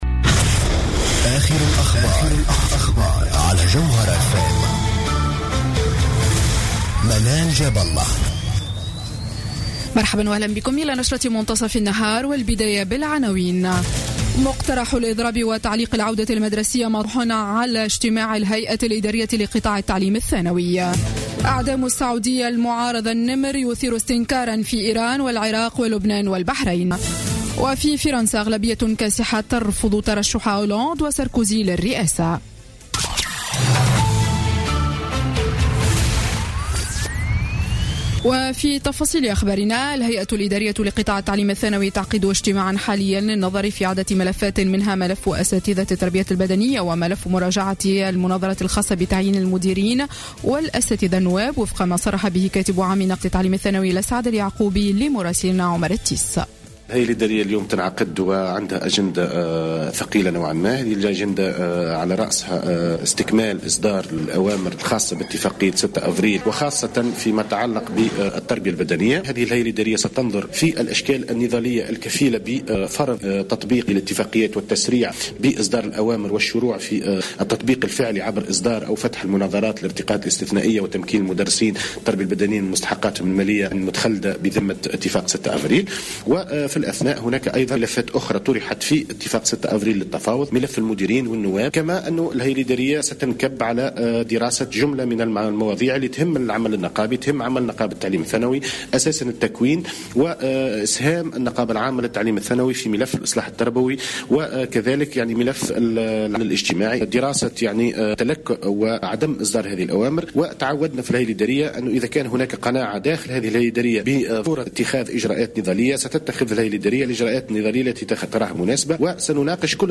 نشرة أخبار منتصف النهار ليوم السبت 2 جانفي 2016